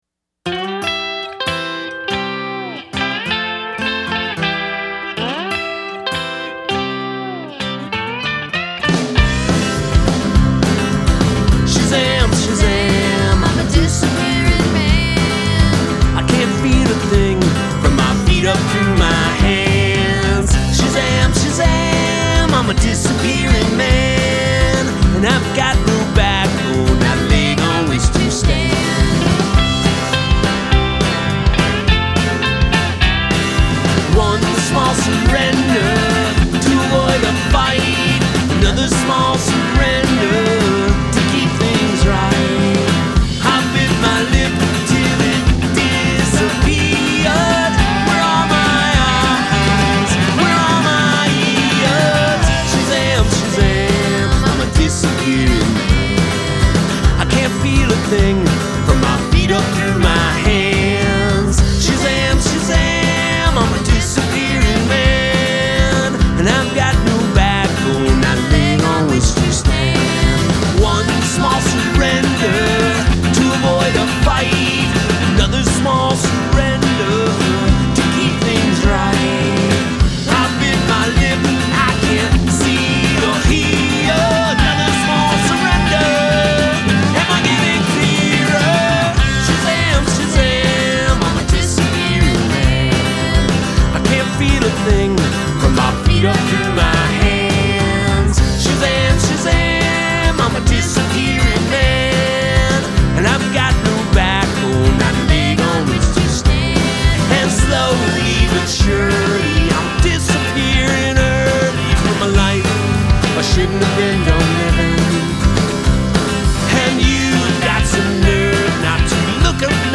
It is all at once beautiful, heavy, uplifting, energizing.